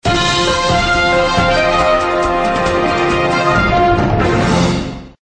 Download Breaking News sound effect for free.